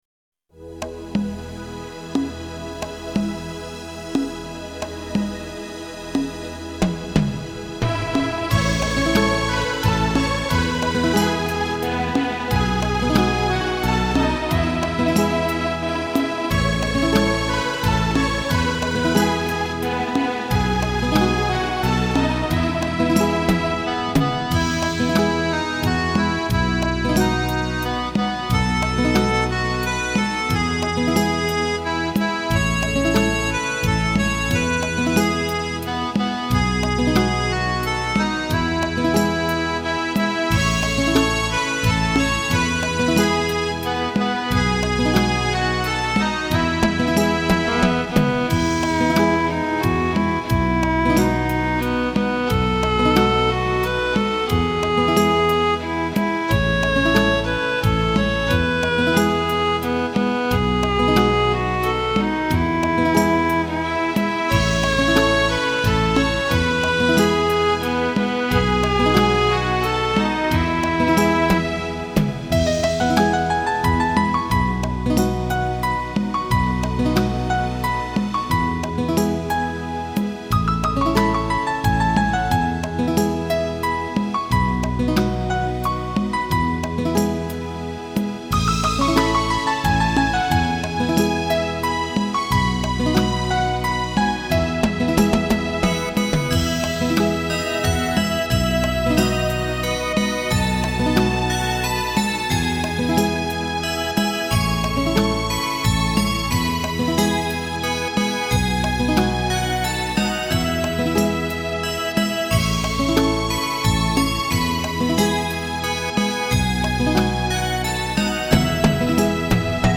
Слушать или скачать минус